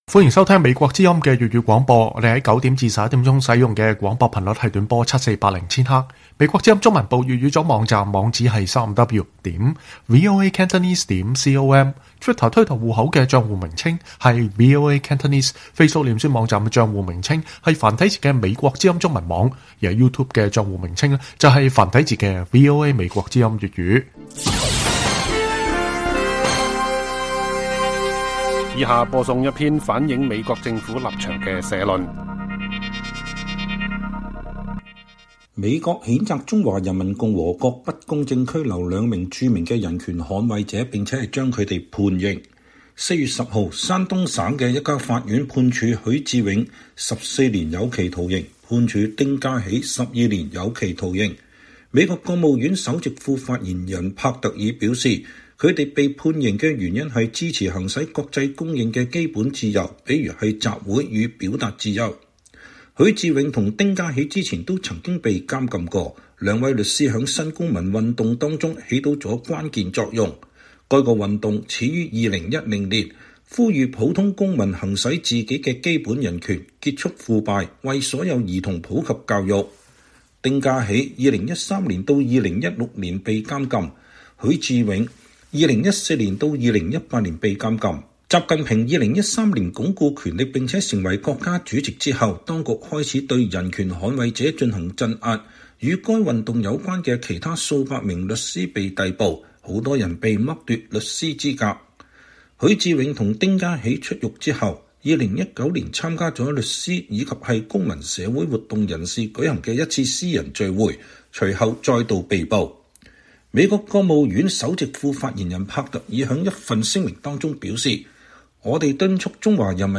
以下是一篇反映美國政府政策立場的社論：